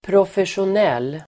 Uttal: [profesjon'el:]